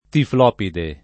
[ tifl 0 pide ]